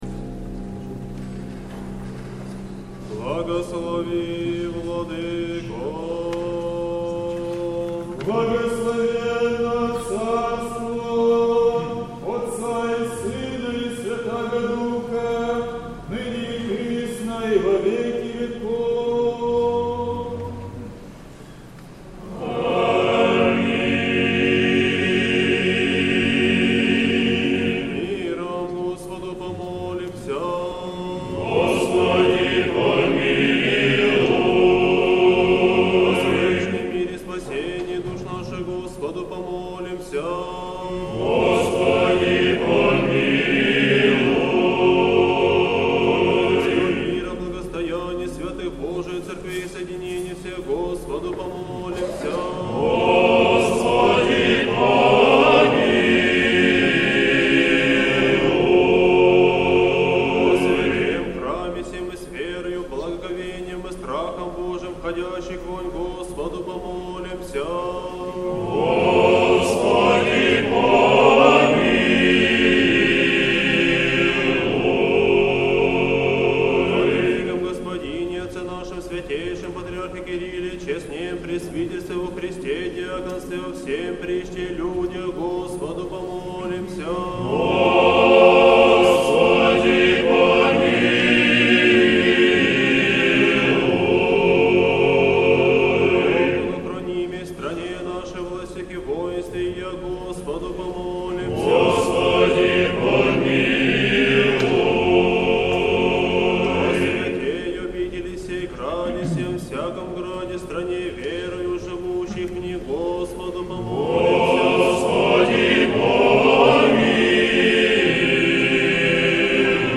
Божественная литургия.
Божественная литургия в Сретенском монастыре в Неделю 8-ю по Пятидесятнице